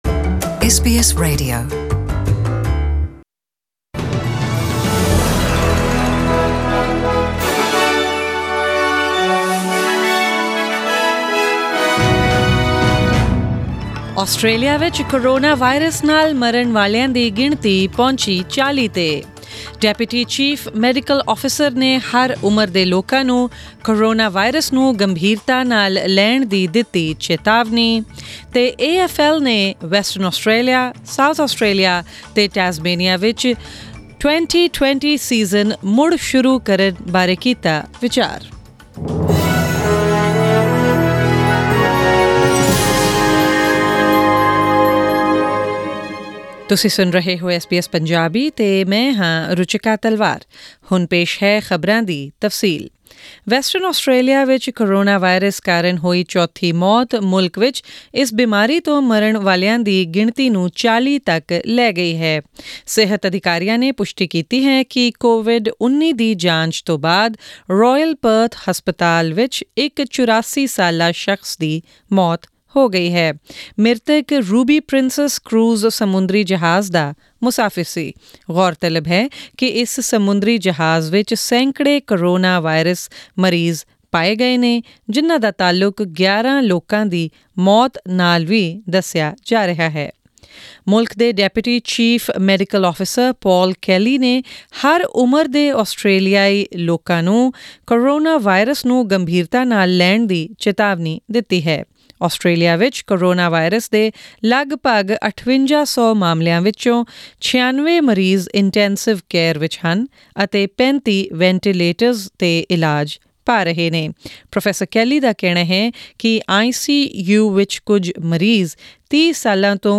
Australian News in Punjabi: 6 April 2020